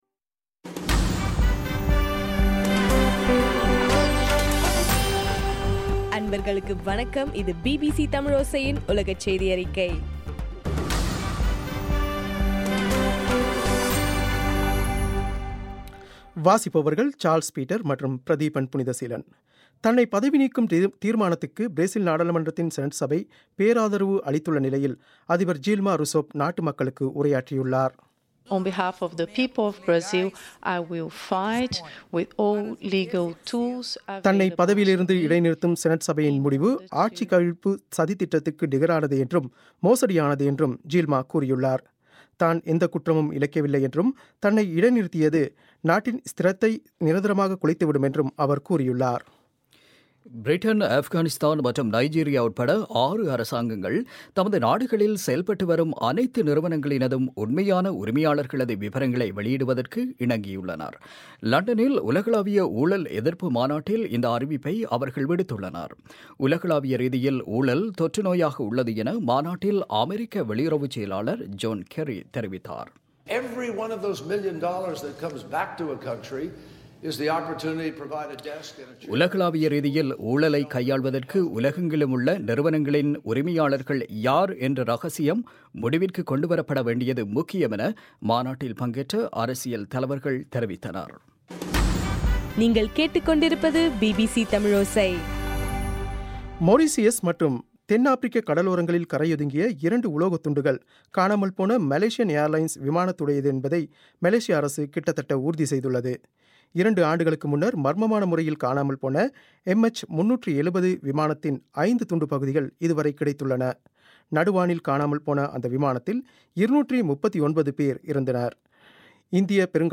மே 12 பிபிசியின் உலகச் செய்திகள்